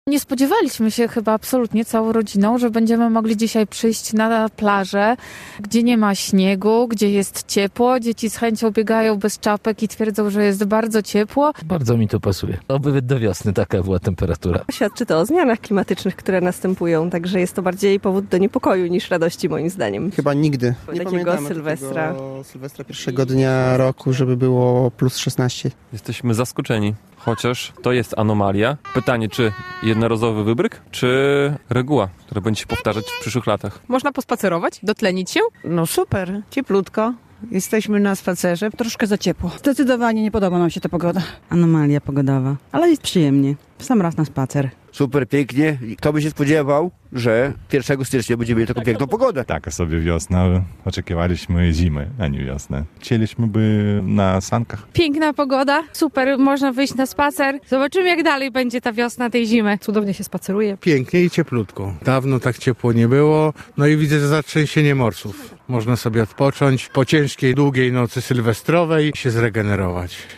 Rekordowe temperatury pierwszego dnia roku - relacja